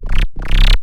55-FROG   -R.wav